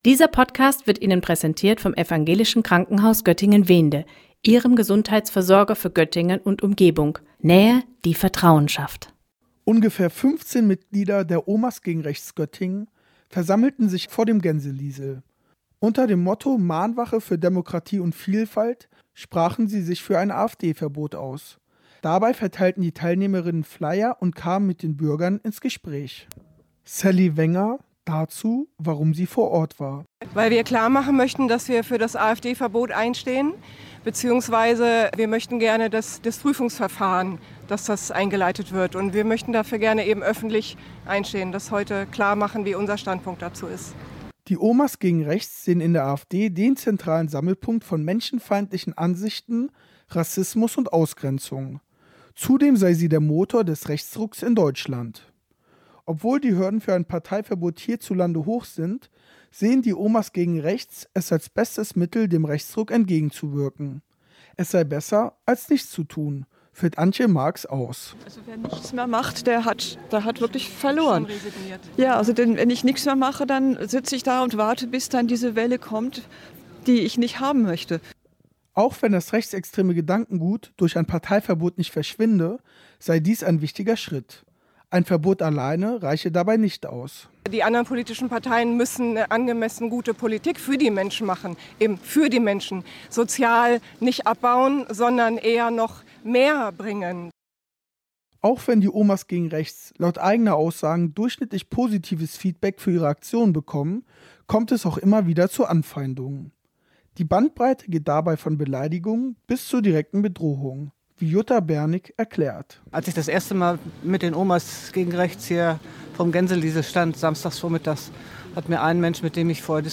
Gestern veranstalteten die „Omas gegen Rechts Göttingen“ eine Mahnwache für ein AfD-Verbot vor dem Gänseliesel.